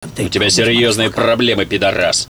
knife.wav